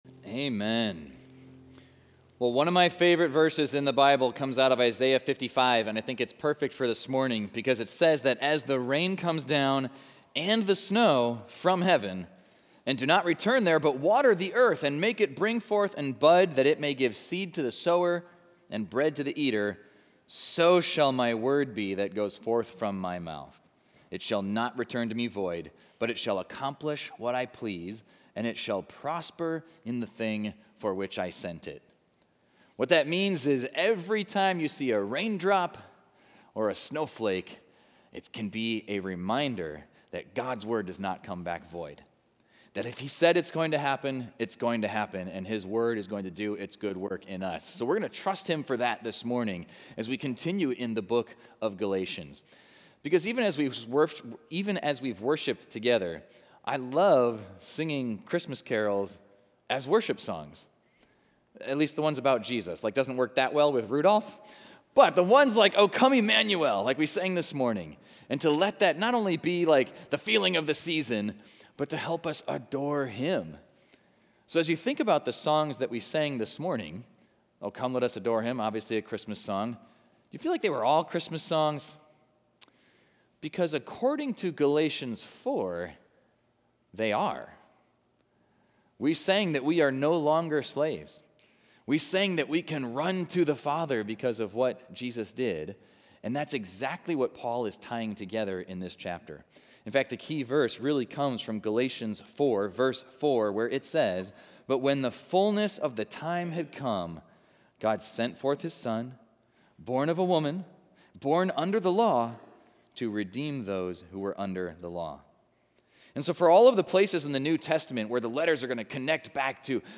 Equipping Service / Galatians: Christmas Heirs / Generous Heirs